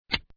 SWITCH3.mp3